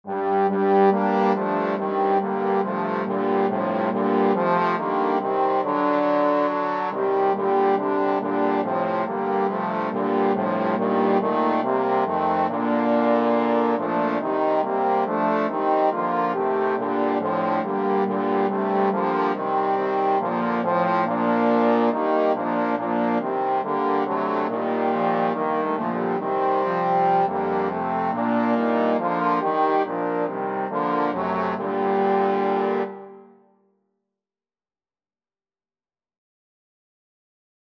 Trombone 1Trombone 2Trombone 3Trombone 4
The melody is in the minor mode.
4/4 (View more 4/4 Music)